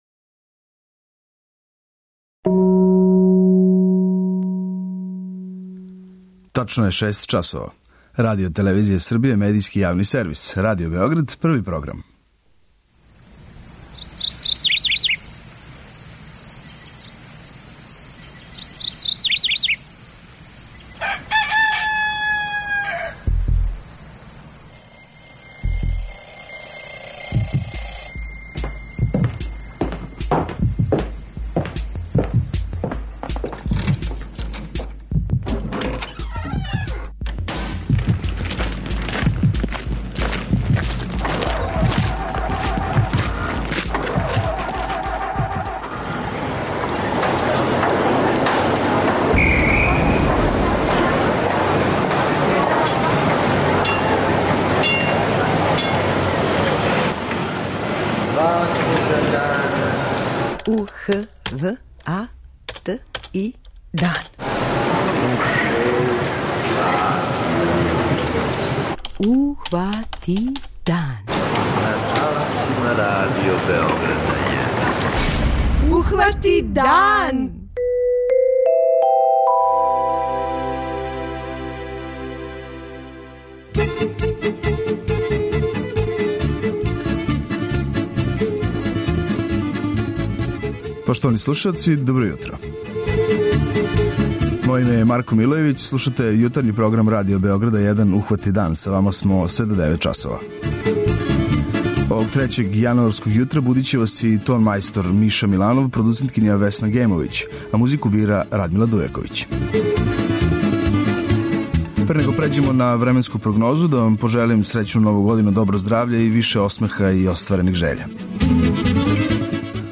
преузми : 85.95 MB Ухвати дан Autor: Група аутора Јутарњи програм Радио Београда 1!